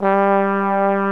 BRASS2-MID.wav